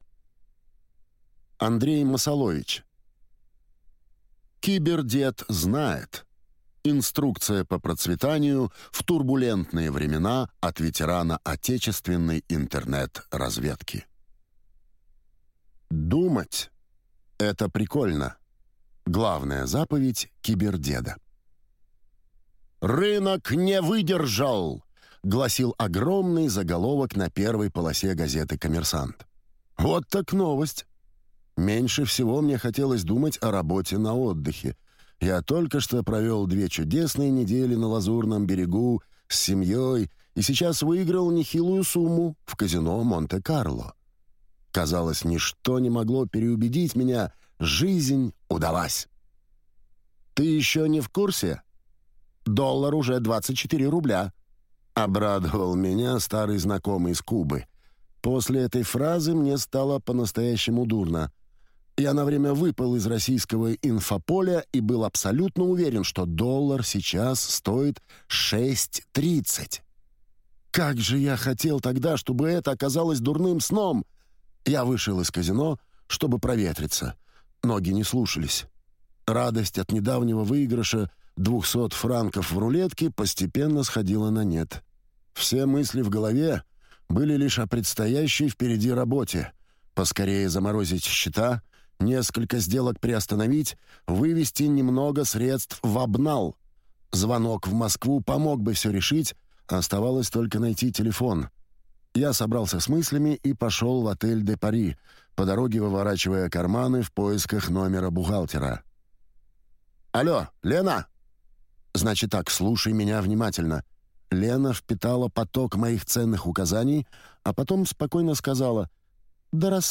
Аудиокнига КиберДед знает. Инструкция по процветанию в турбулентные времена от ветерана отечественной интернет-разведки | Библиотека аудиокниг